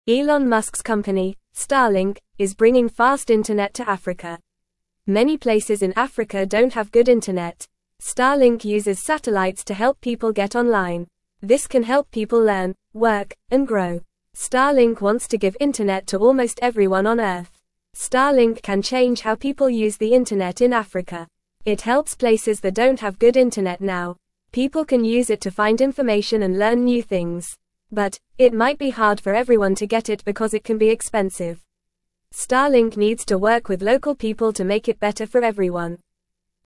Fast
English-Newsroom-Beginner-FAST-Reading-Starlink-brings-fast-internet-to-Africa-to-help-people.mp3